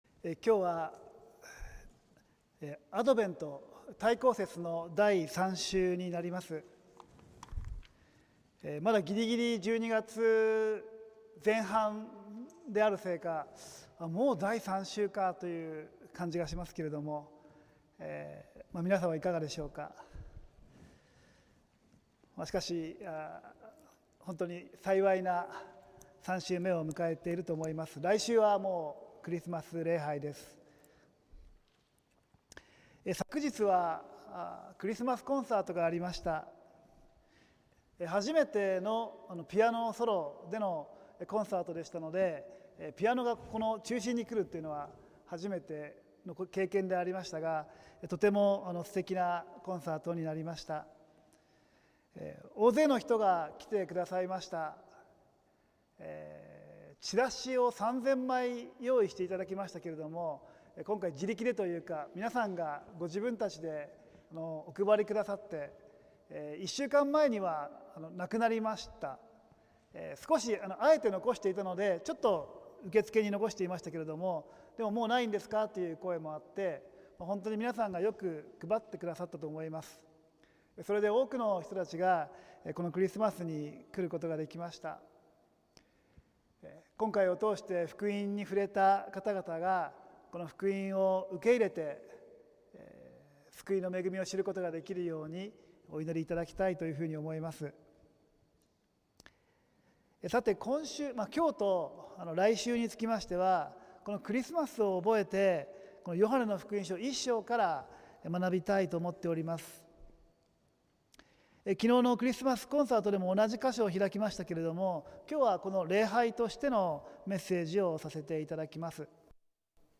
浦和福音自由教会(さいたま市浦和区)の聖日礼拝(2025年12月14日)「まことの光キリスト」(週報とライブ/動画/音声配信)